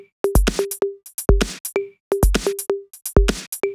Index of /VEE/VEE2 Loops 128BPM
VEE2 Electro Loop 085.wav